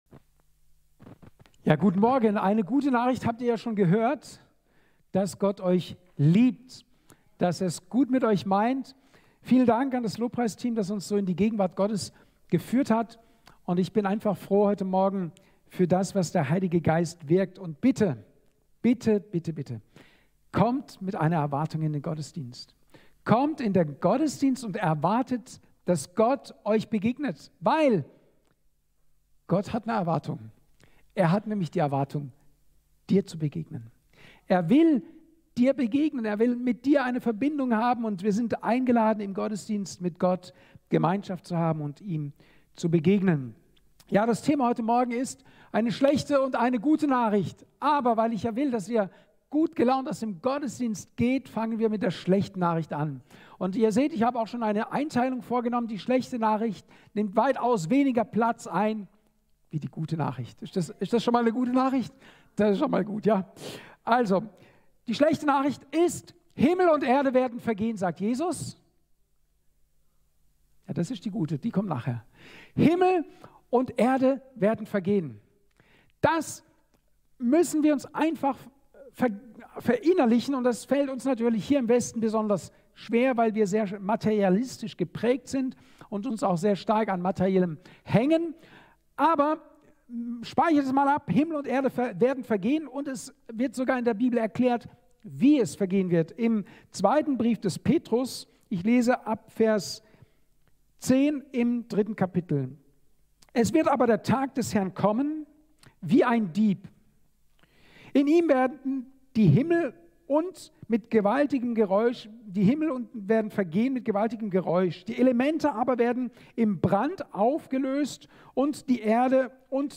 28.01.2024 Ort: Gospelhouse Kehl